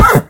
horse_hit2.ogg